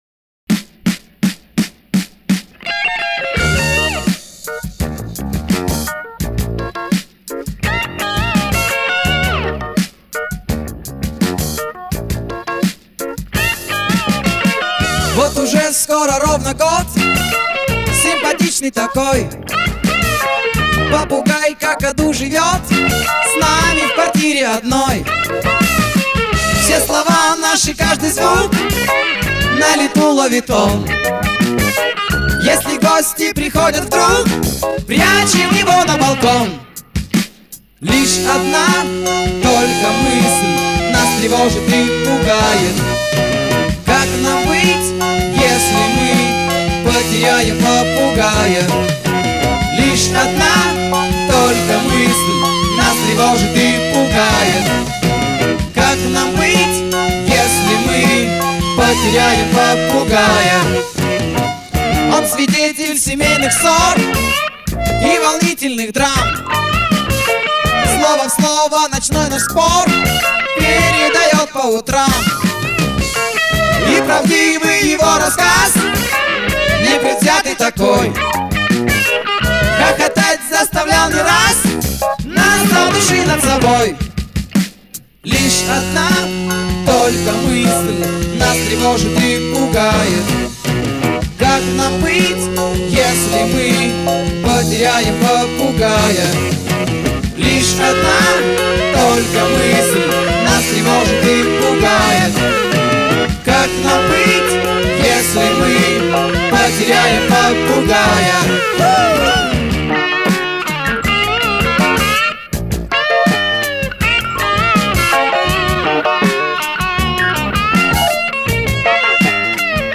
Жанр: Rock
Стиль: Hard Rock, Soft Rock, Pop Rock